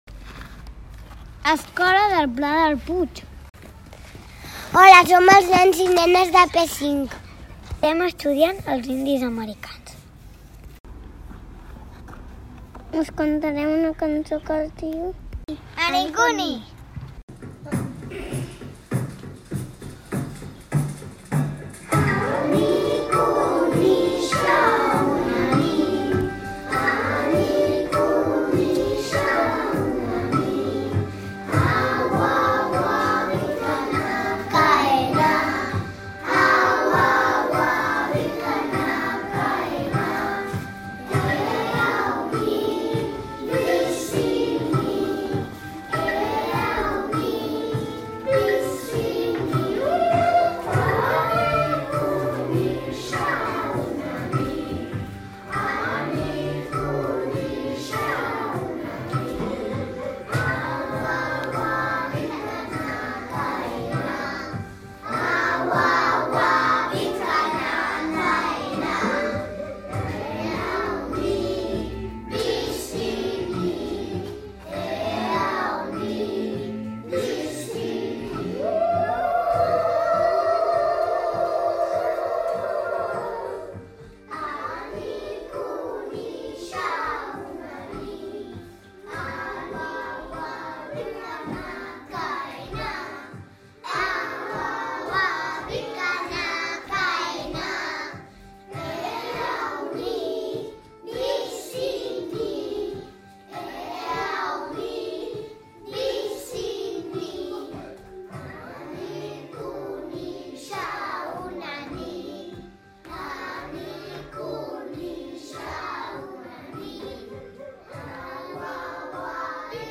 Els nens i nenes de P5 hem après una cançó dels indis americans “Anikuni”, que prové de la tribu dels iroquesos i ens podeu escoltar a ràdio Sant Fruitós ( programa Infoescoles, us deixarem l’enllaç).
Si ens voleu sentir cantar i també ens voleu veure ballar …